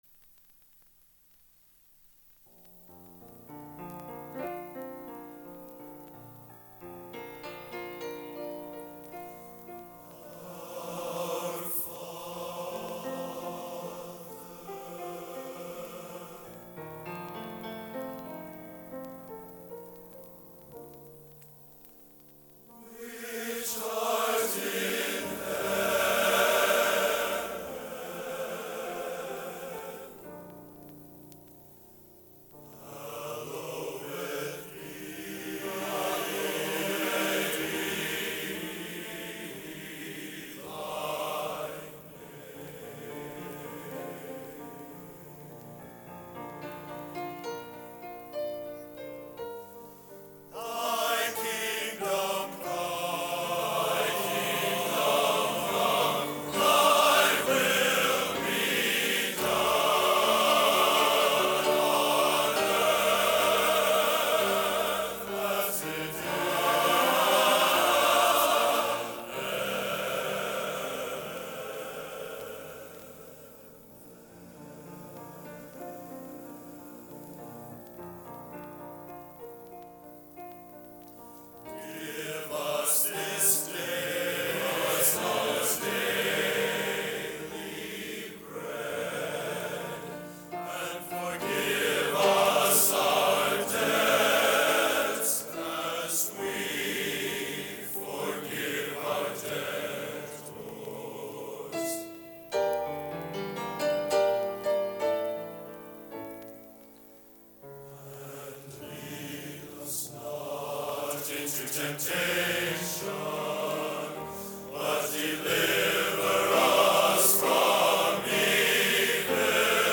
Location: West Lafayette, Indiana
Genre: Sacred | Type: Studio Recording